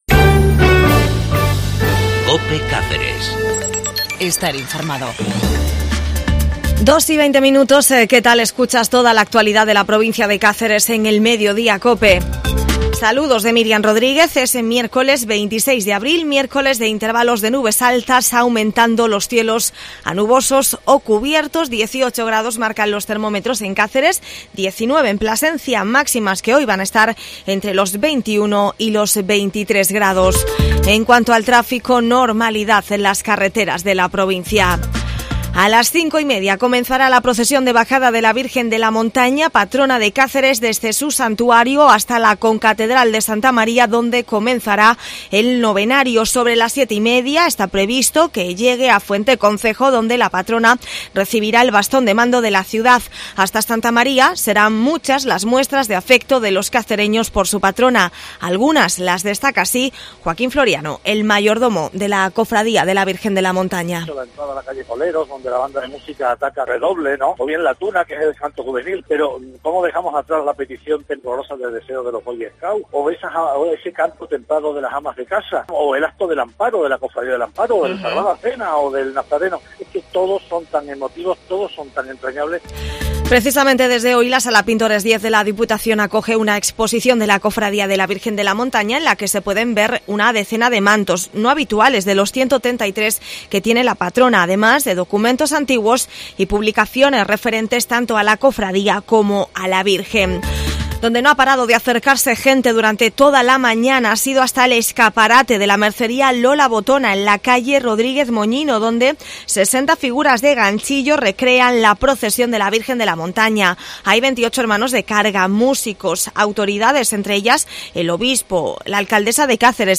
AUDIO: INFORMATIVO LOCAL CACERES